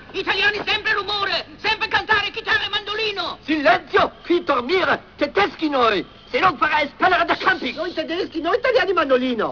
Ecco l'avete fatto incazzare! meglio se suonate il mandolino!
mandolino.wav (53.8 k)